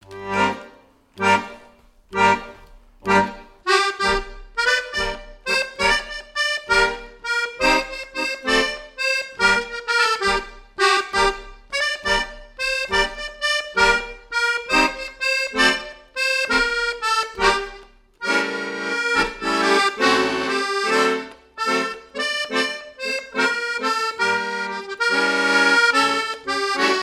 Mémoires et Patrimoines vivants - RaddO est une base de données d'archives iconographiques et sonores.
danse : quadrille : poule
Pièce musicale inédite